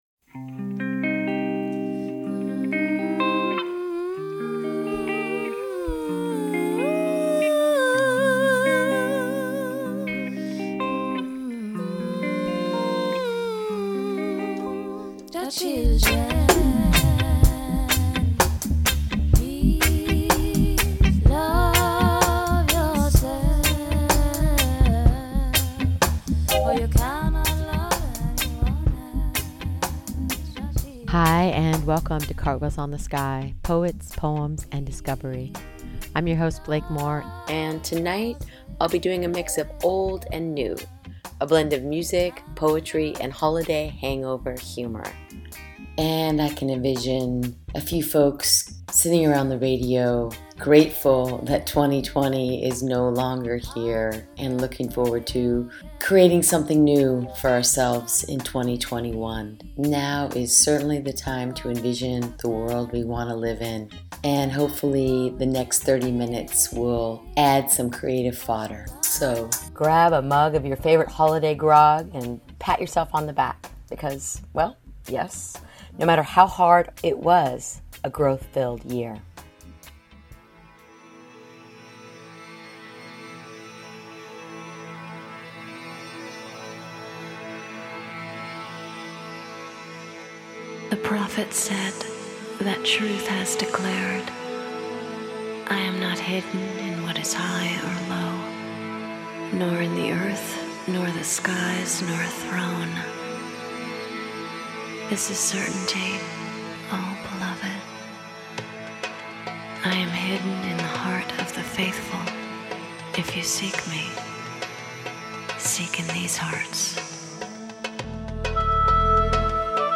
This week’s Cartwheels on the Sky features a mix of poetry, words and music. A blend of past and present, the next 30 minutes is my way of ushering in the long-anticipated 2021.